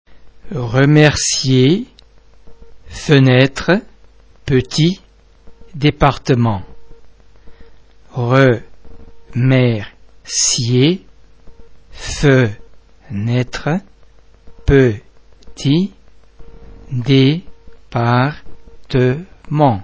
·[ e ] in polysyllabic words where the syllable ends in e :